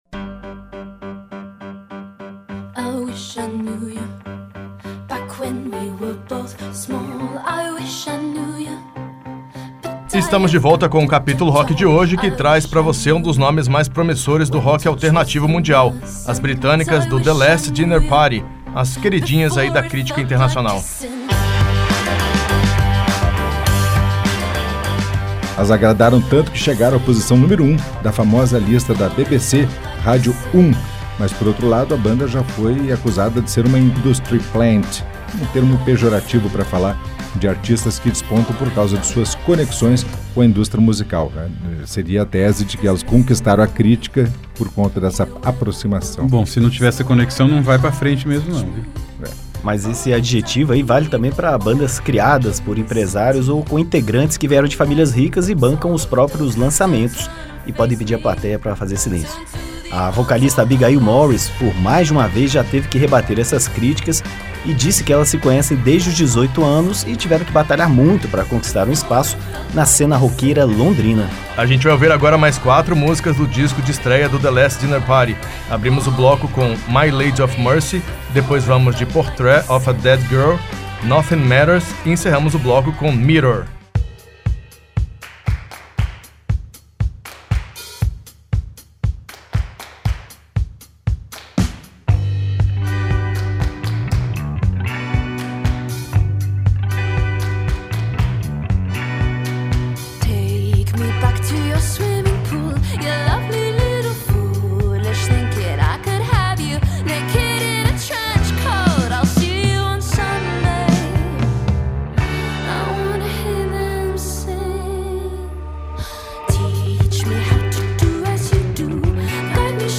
nos vocais principais
na guitarra solo, bandolim e flauta
baixo